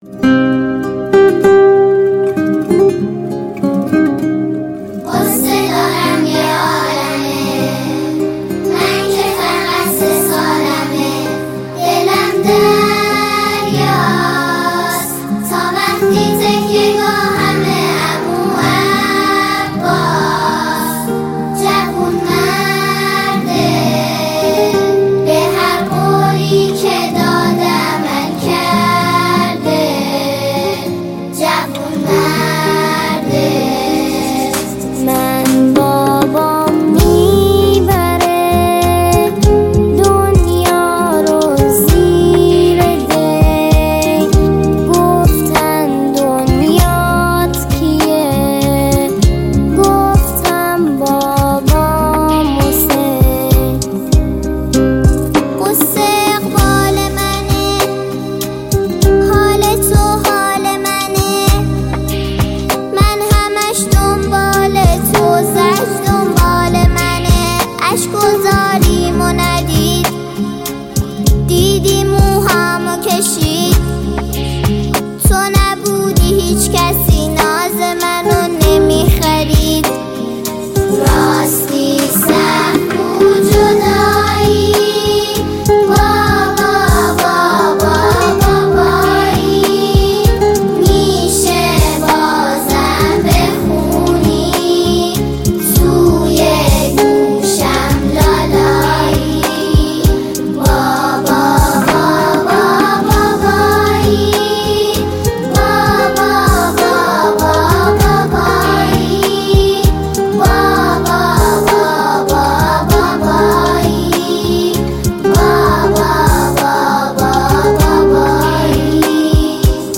نماهنگ جدید